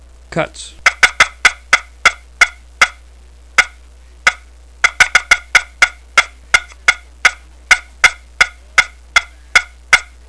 Listen to 10 seconds of cutts